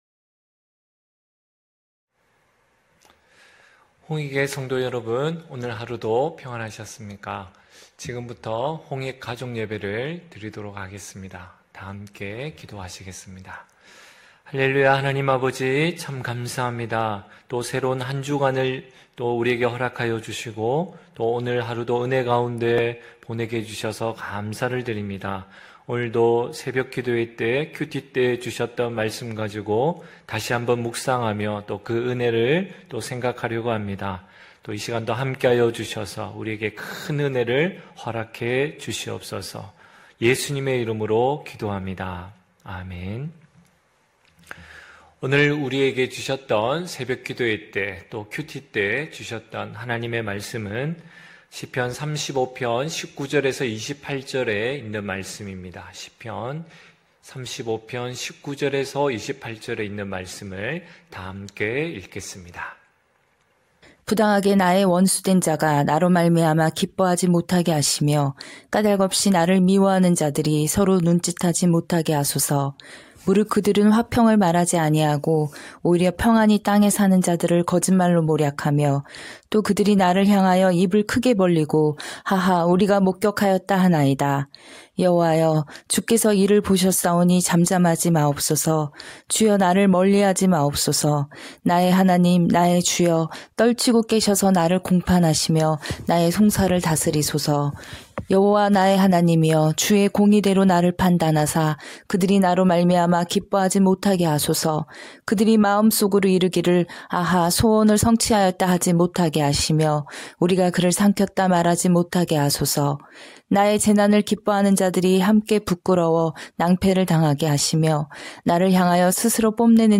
9시홍익가족예배(10월26일).mp3